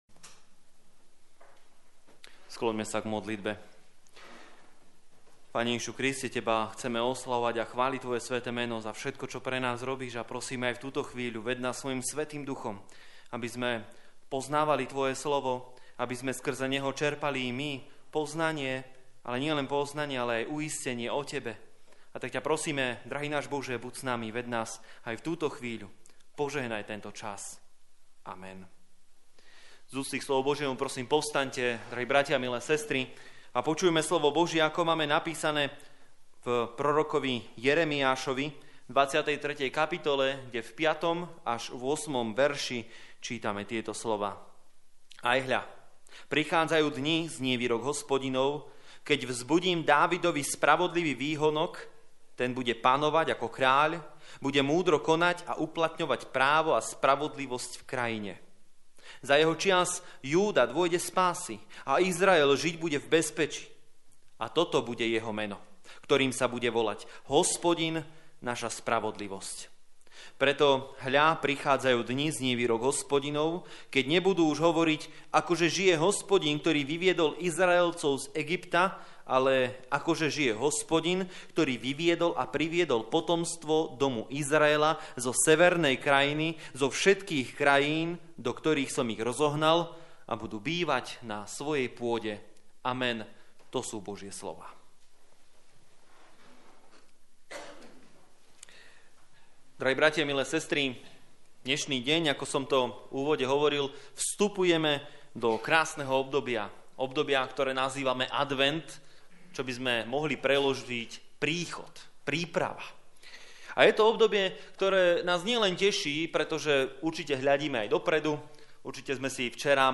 Service Type: Služby Božie